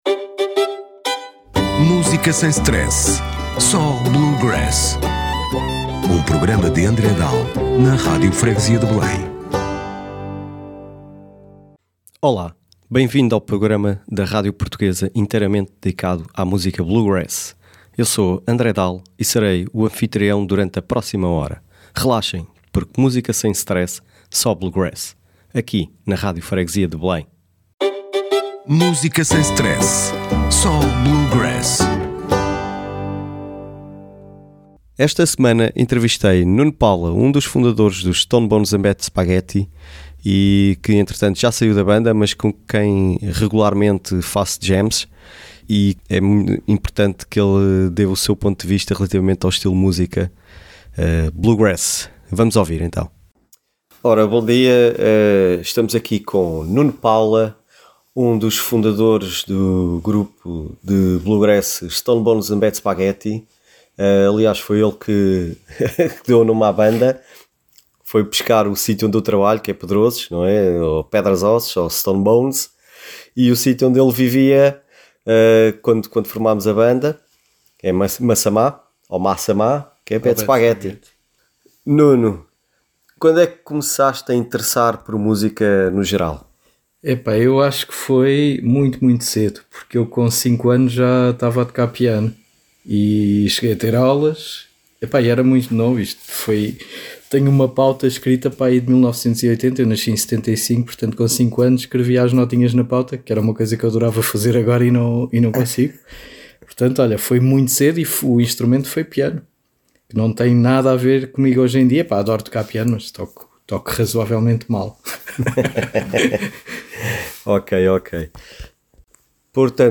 Música sem stress, só bluegrass.